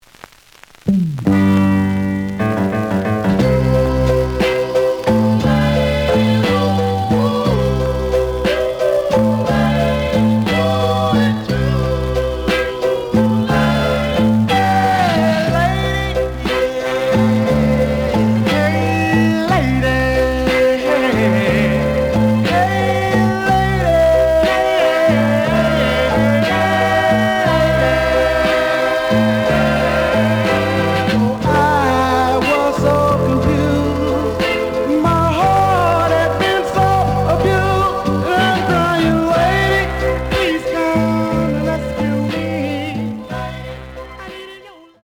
The audio sample is recorded from the actual item.
●Genre: Soul, 60's Soul
Some noise on A side.